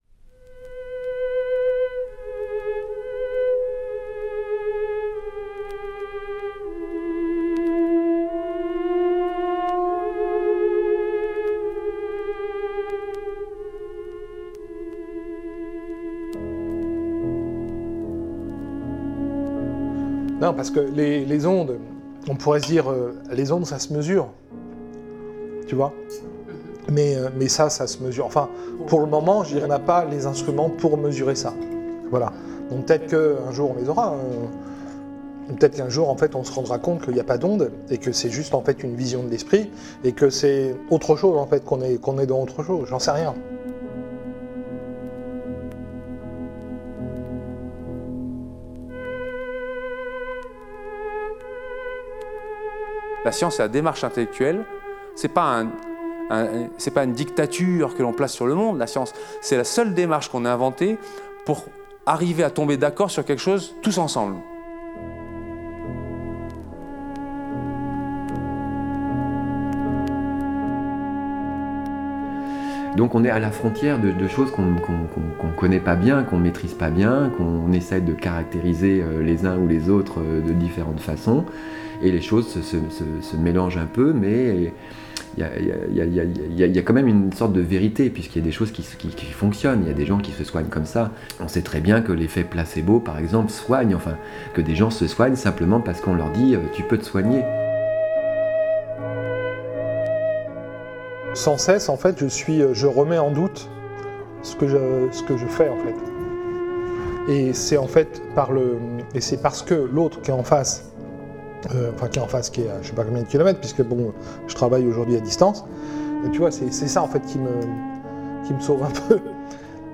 Ondes Martenot
Piano